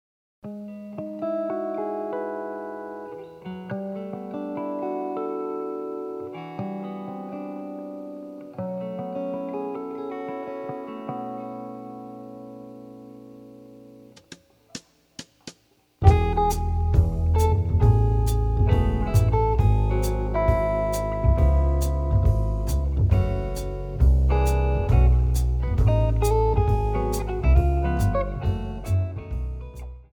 seven-string acoustic guitar